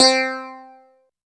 69 CLAV C4-R.wav